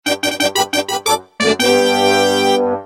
race fanfare.mp3